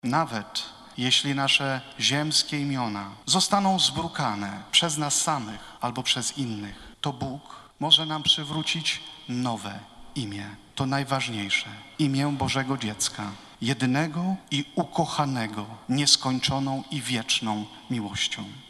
Z tej okazji w katedrze świętego Michała Archanioła i świętego Floriana Męczennika odprawiona została uroczysta msza święta z udziałem biskupów metropolii warszawskiej, na czele z kardynałem Kazimierzem Nyczem i abp. Tadeuszem Wojdą.